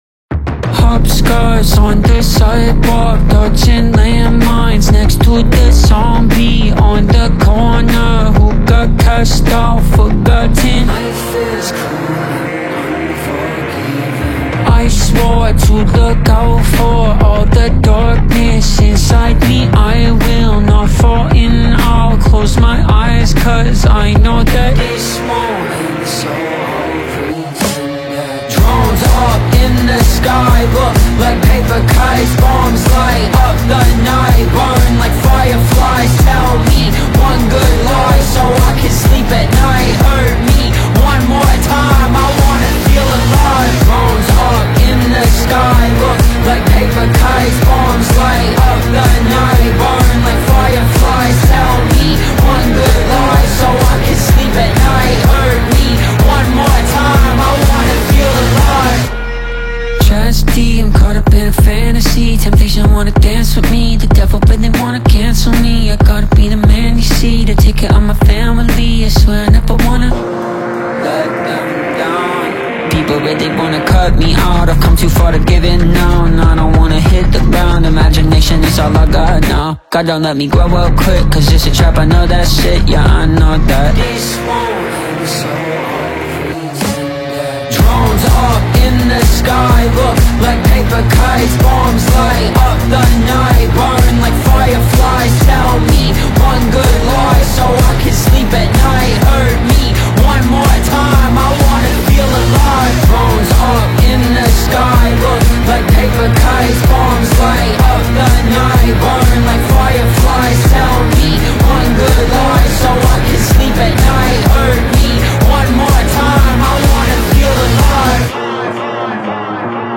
Hip Hop
catchy song